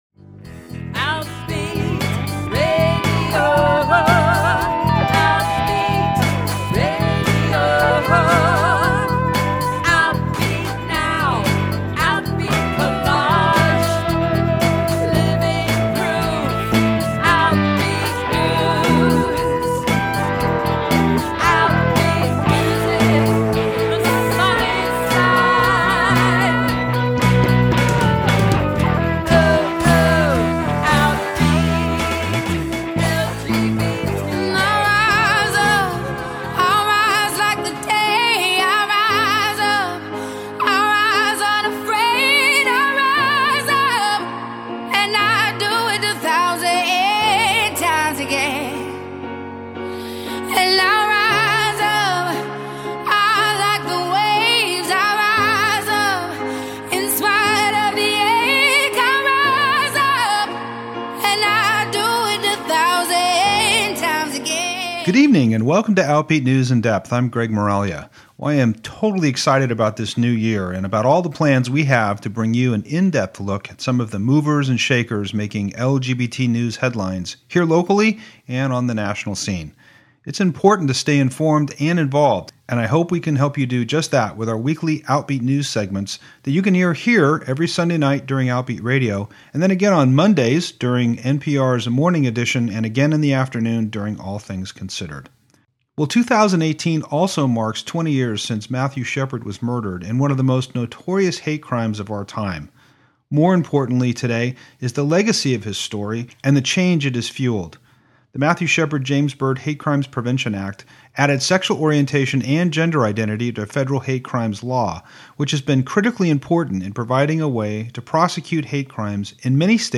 This month we take you to Denver, Colorado and share highlights from this year’s gala.